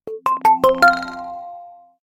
Catégorie Telephone